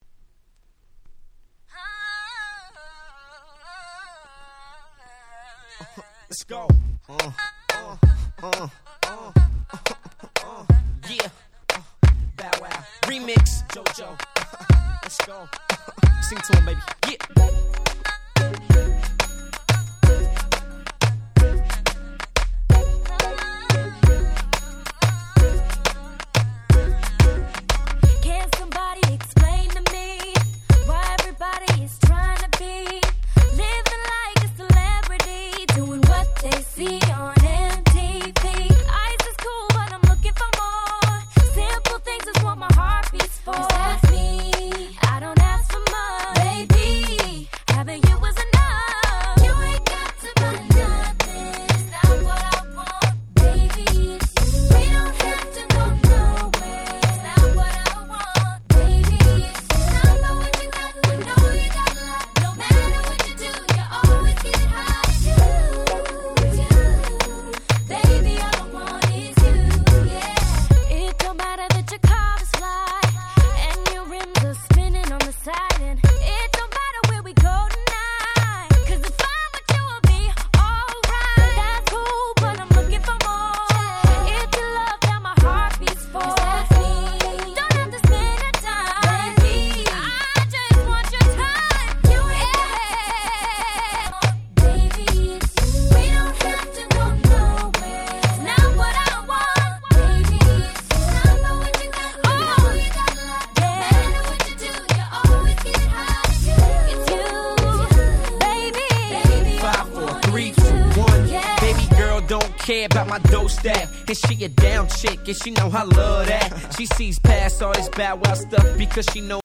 04' Smash Hit R&B !!
可愛いVocalが堪りません！
キャッチー系